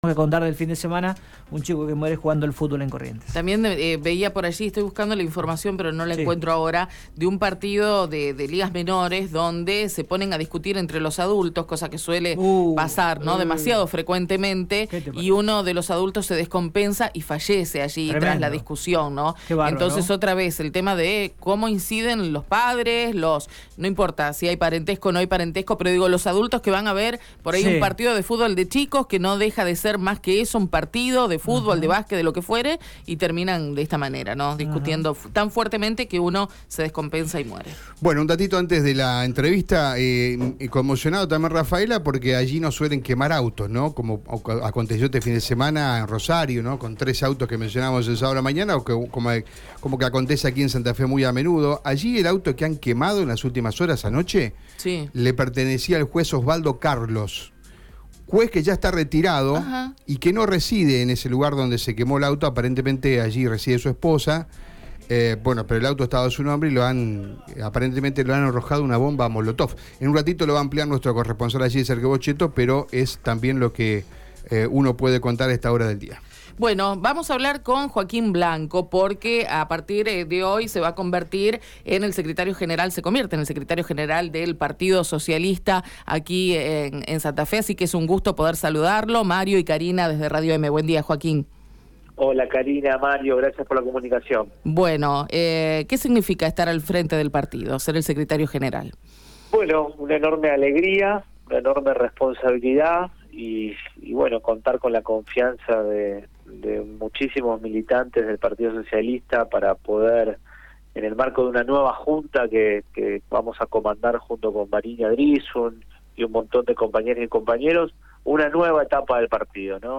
Escucha la palabra de Joaquín Blanco en Radio EME: